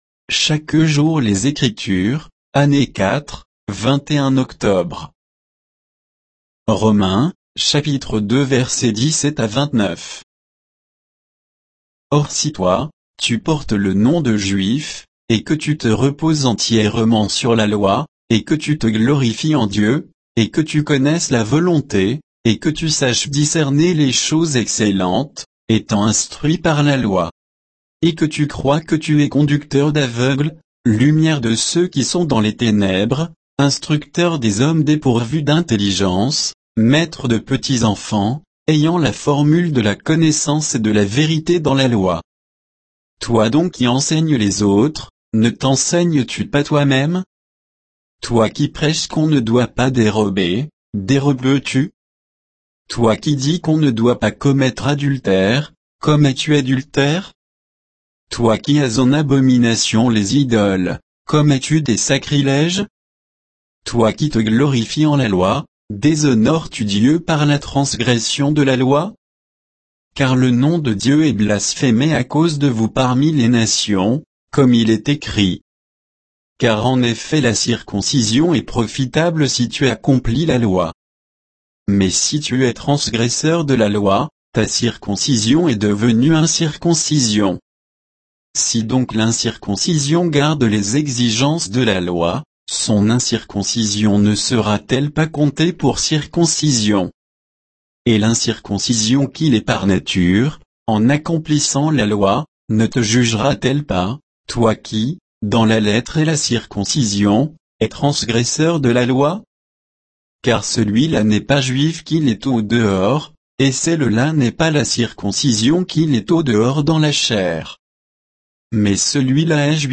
Méditation quoditienne de Chaque jour les Écritures sur Romains 2